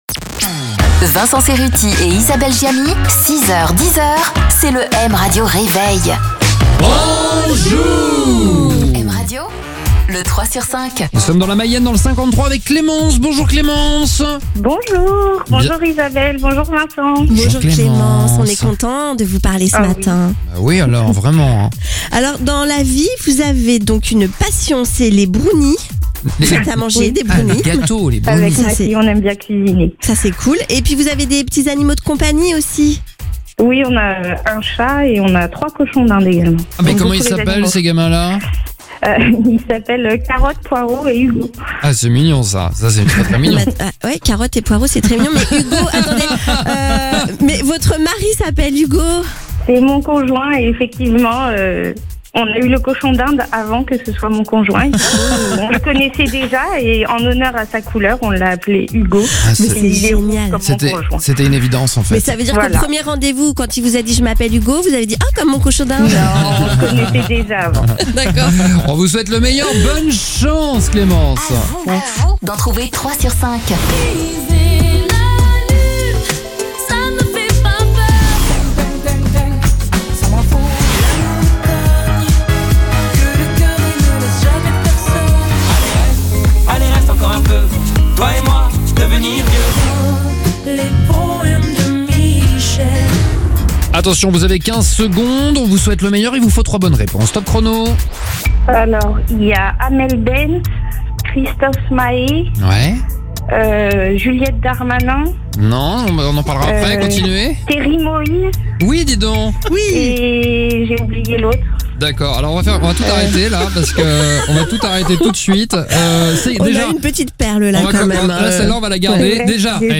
Reconnaissez 3 artistes sur les 5 présents dans le medley et repartez avec un cadeau.